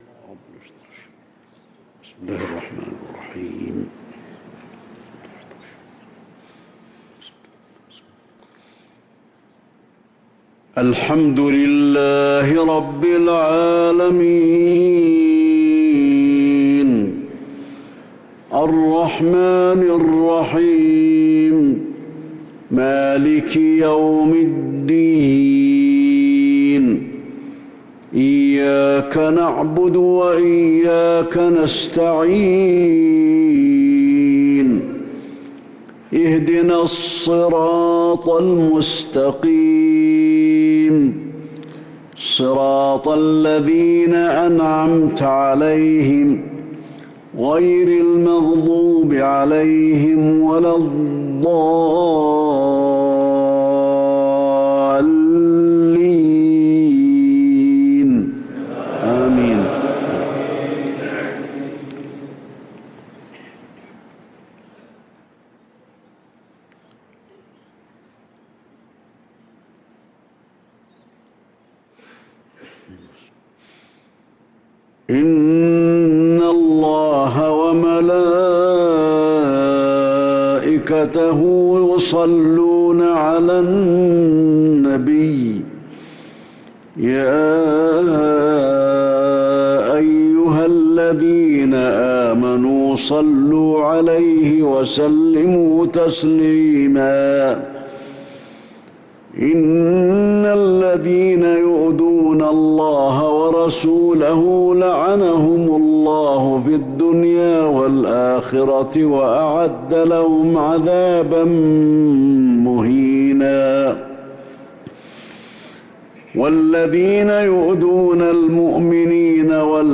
صلاة العشاء للقارئ علي الحذيفي 2 محرم 1445 هـ
تِلَاوَات الْحَرَمَيْن .